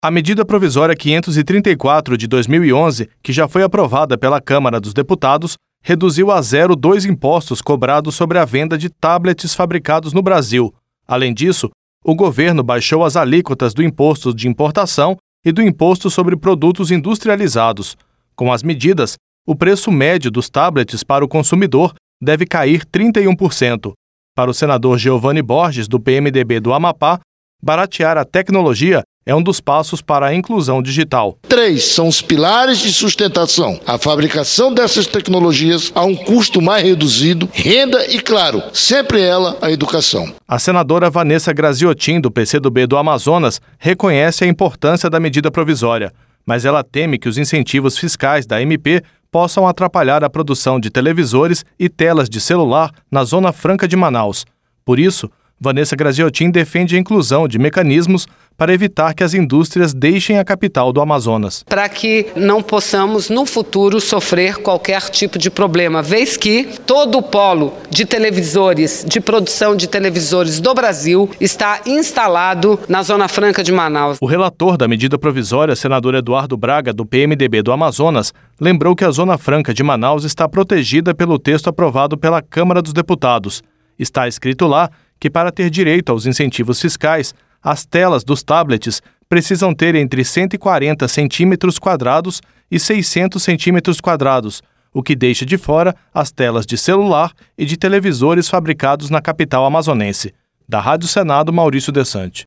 Tecnologia